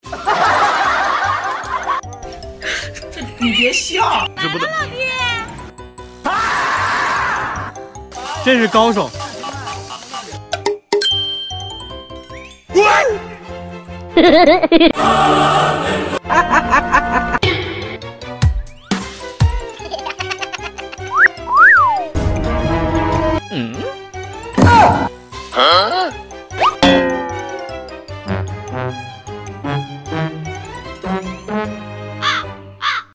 素材介绍 素材主要用于制作抖音、快手、火山、微视等自媒体搞笑短视频，全部为热门音效，包含各种搞笑梗音效，有试听。